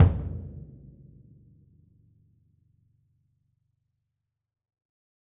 Percussion
BDrumNewhit_v6_rr2_Sum.wav